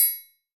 {Snr} goosebumpstri.wav